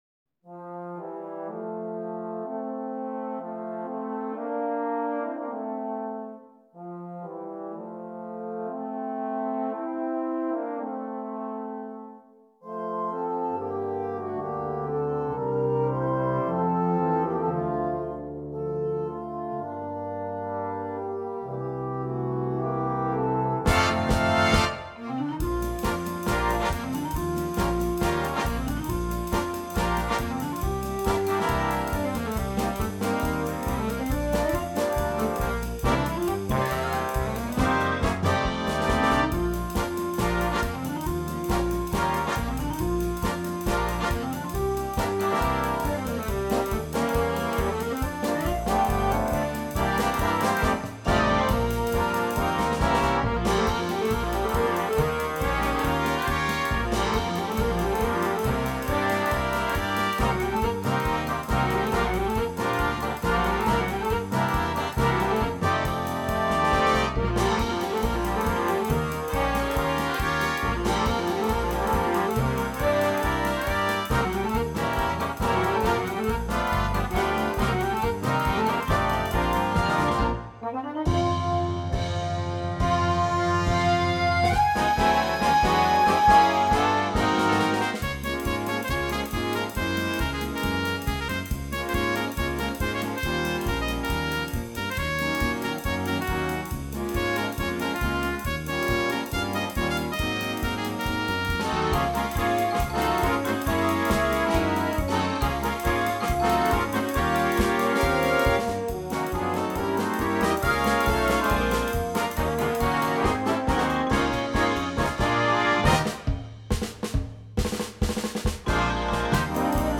Gattung: Polka-Swing
Besetzung: Blasorchester